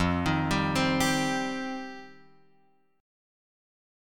F7sus2 chord {1 x 1 0 1 3} chord